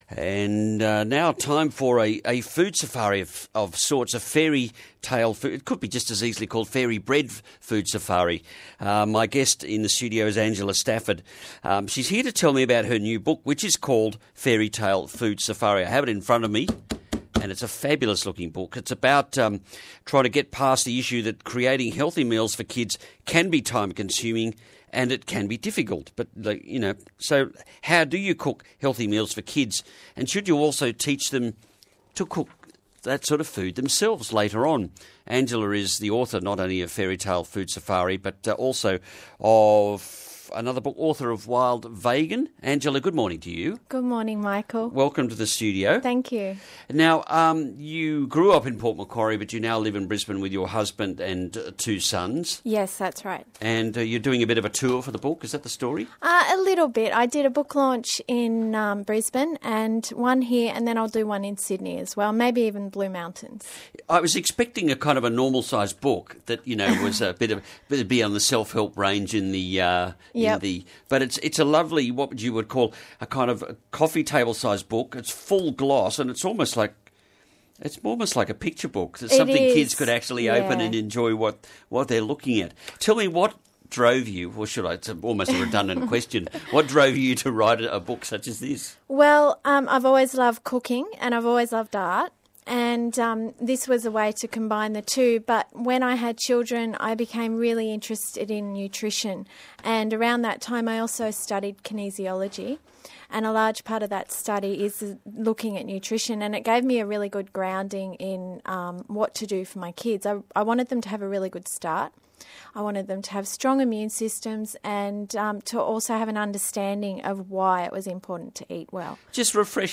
ABC Midnorth Coast Radio Interview